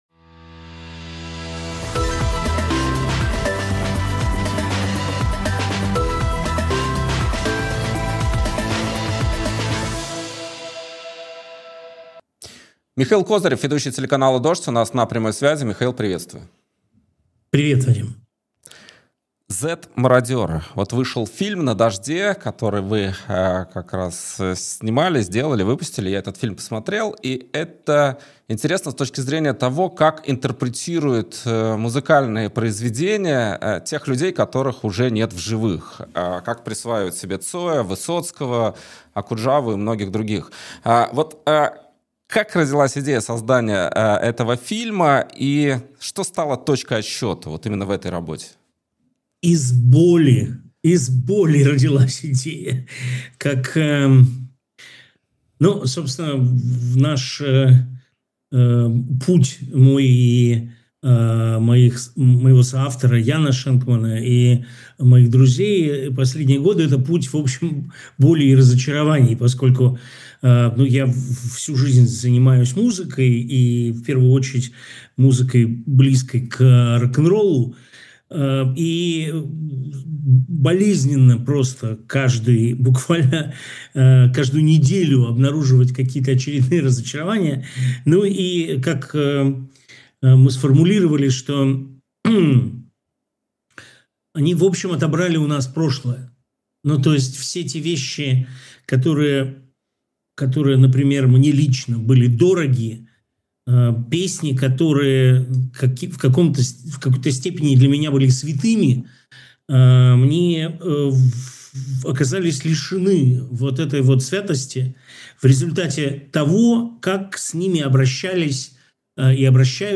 Эфир ведёт Вадим Радионов